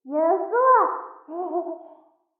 三楼/囚室/肉铺配音偷听效果处理；
c03_三楼窗户人影偷听_小小蝶17.ogg